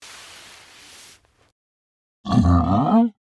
Zombie Huh
Zombie Huh is a free horror sound effect available for download in MP3 format.
Zombie Huh.mp3